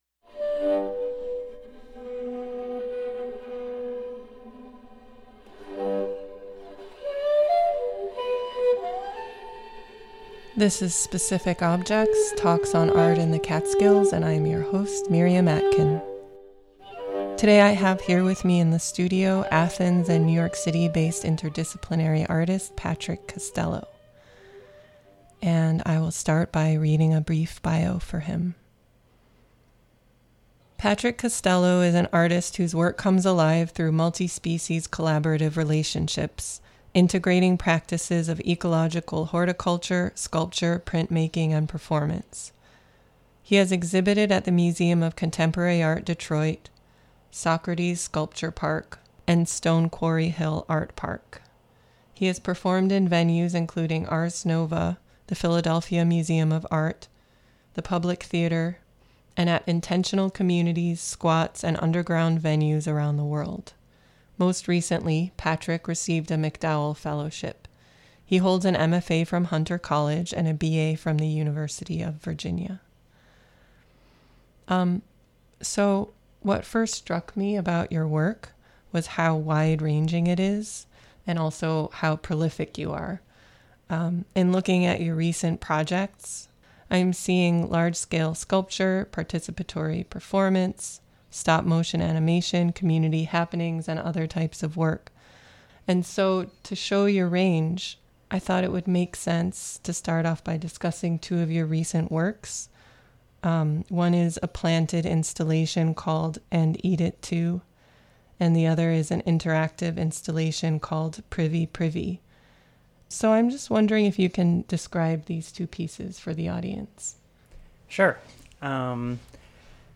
Intro music